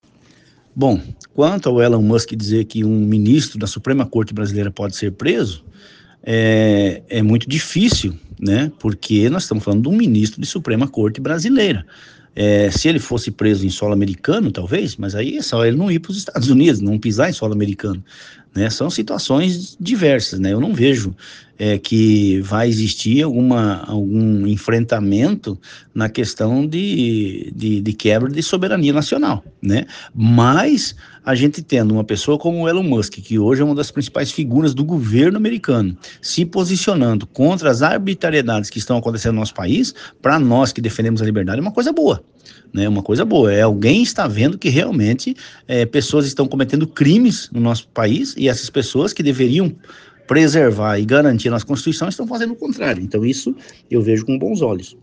OUÇA A ENTREVISTA DO DEPUTADO GILBERTO CATTANI